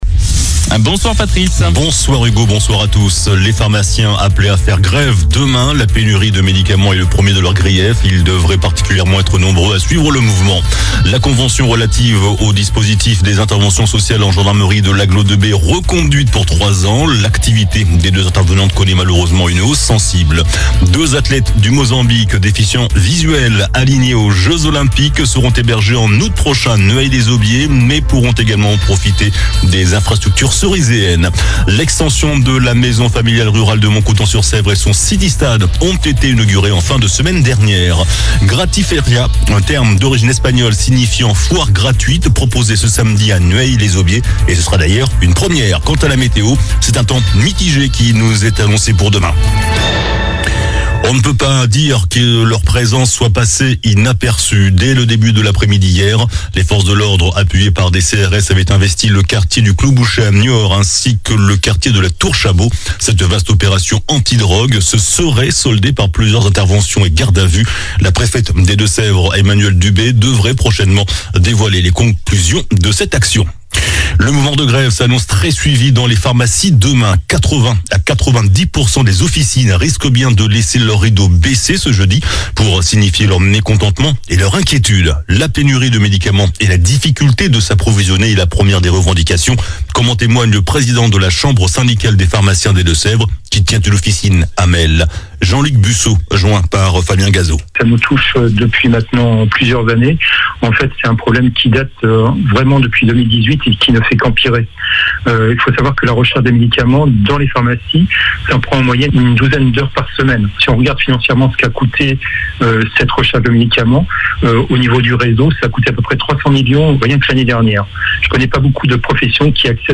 JOURNAL DU MERCREDI 29 MAI ( SOIR )